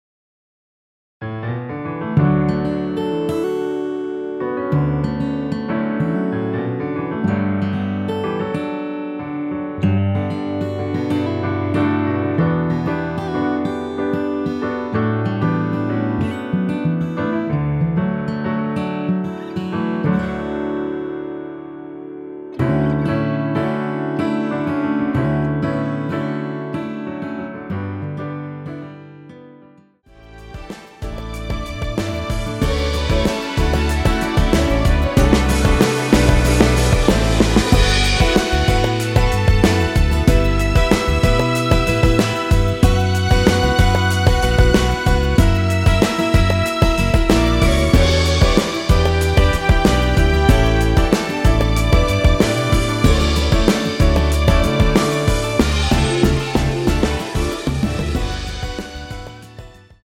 원키에서(+1)올린 MR입니다.
◈ 곡명 옆 (-1)은 반음 내림, (+1)은 반음 올림 입니다.
앞부분30초, 뒷부분30초씩 편집해서 올려 드리고 있습니다.
중간에 음이 끈어지고 다시 나오는 이유는